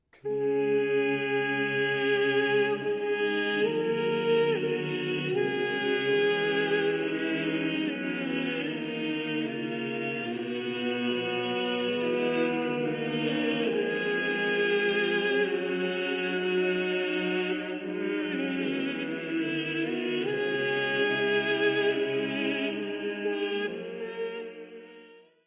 Zetting met kwint- en oktaafparallellen (begin van de Messe de Notre Dame van Guillaume de Machault, ca. 1370)
Uitgevoerd door Ensemble Gilles Binchois.